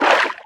Sfx_creature_featherfish_swim_fast_06.ogg